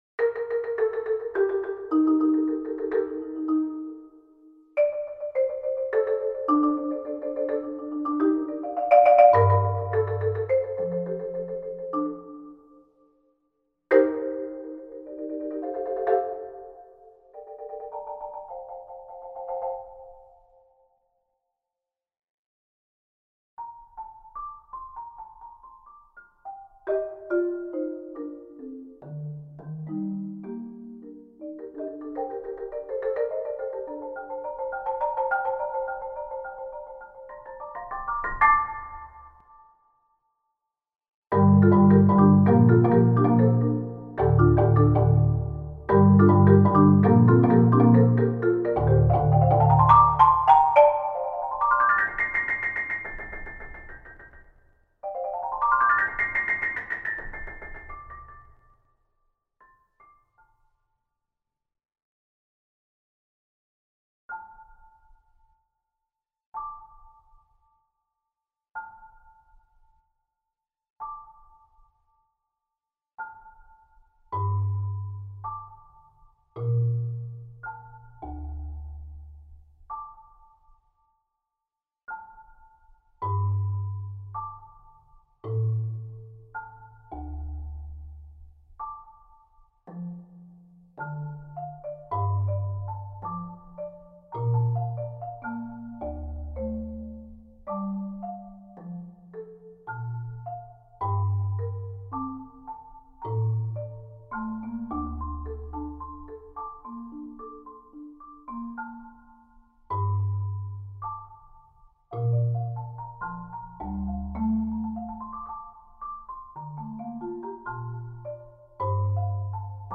Genre: Percussion Ensemble
# of Players: 4
Marimba 1 (4.3-octave) + Garden Weasel
Marimba 2 (4-octave) + Bamboo Wind Chimes
Marimba 3 (4-octave) + Ocean Drum
Marimba 4 (5-octave)